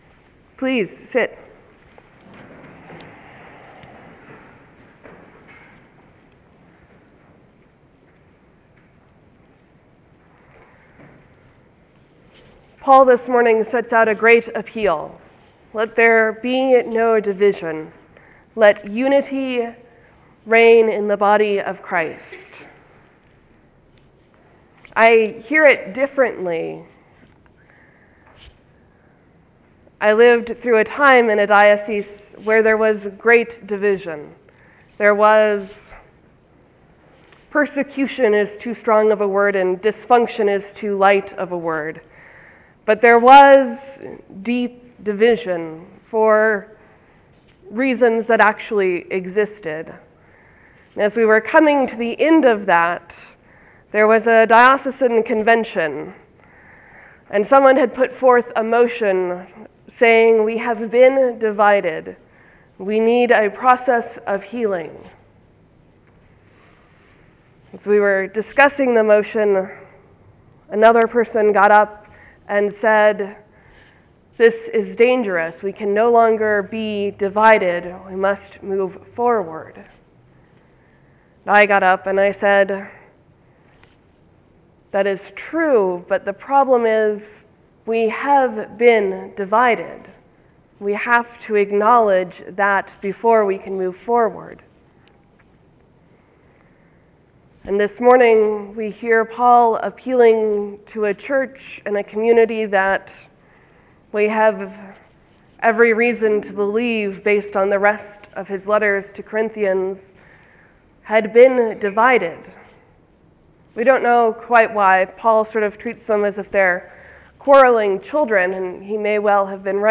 Paul and the Beer Rule, a sermon for 26 Jan 2014